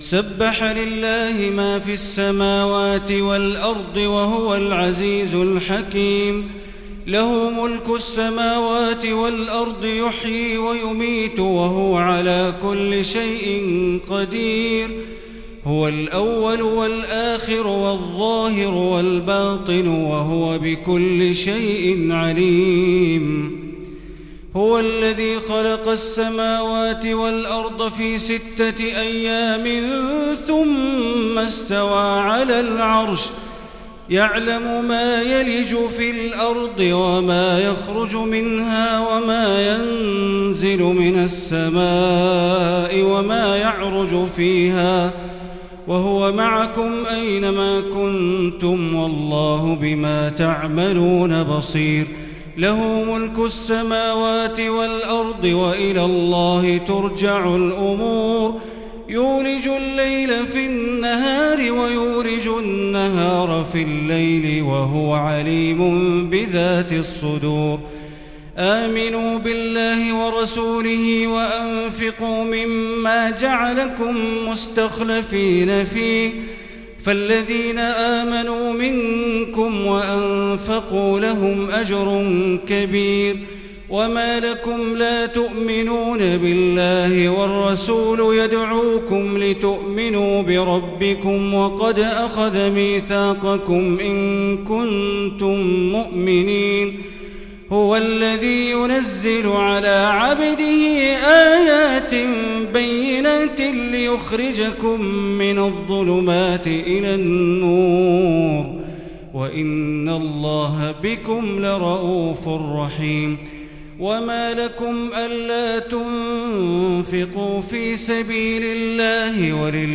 Tarawih prayer on the night of the twenty-eighth of Ramadan for the year 1441 recited by Surah AlHadid and AlMujadilah > 1441 > Taraweeh - Bandar Baleela Recitations